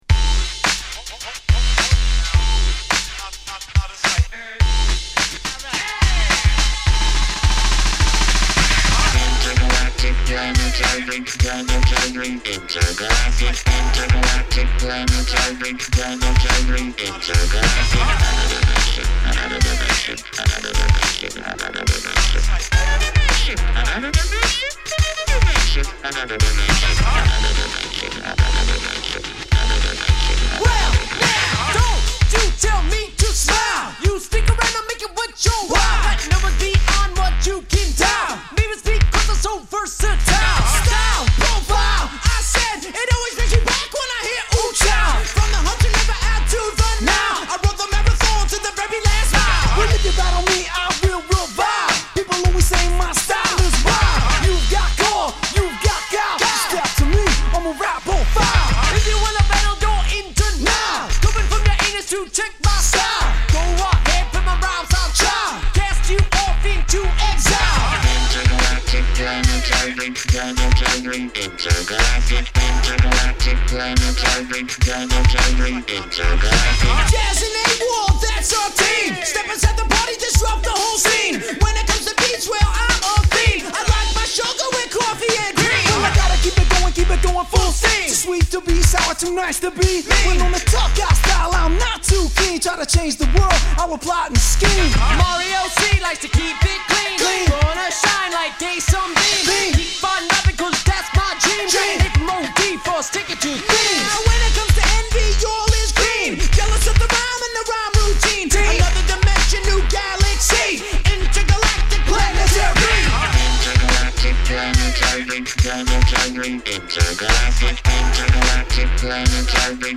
シンセを多用したエレクトリックなA2